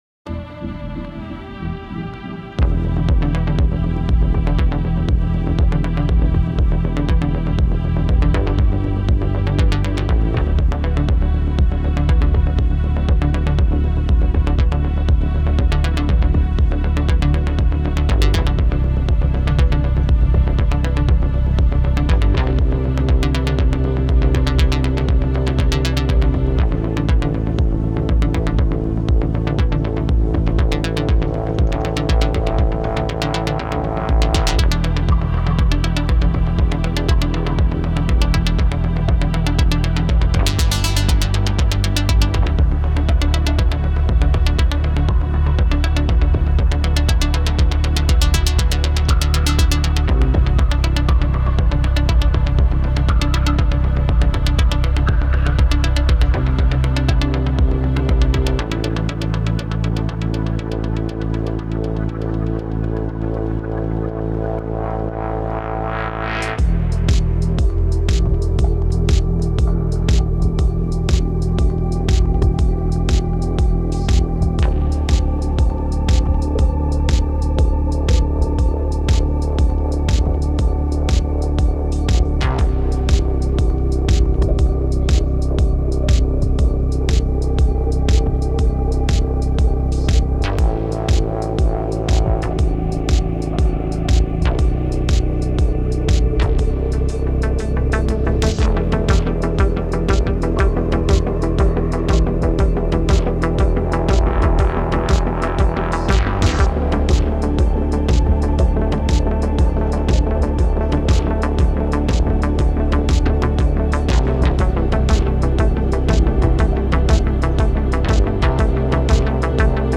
Cinematic Electronica